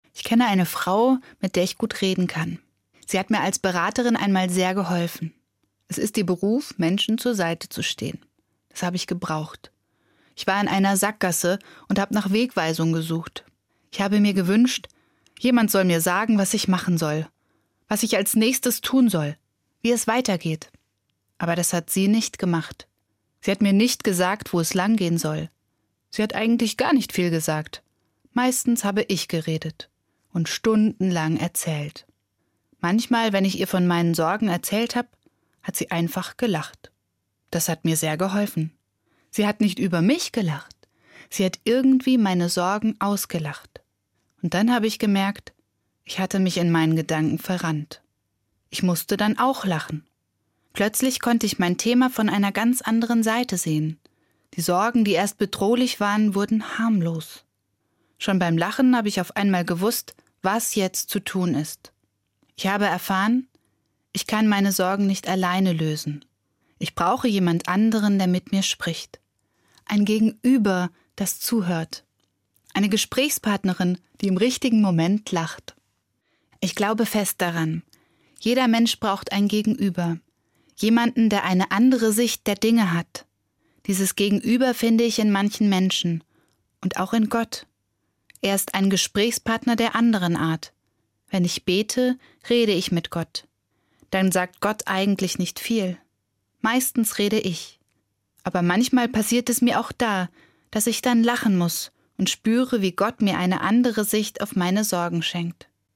Evangelische Pfarrerin, Gießen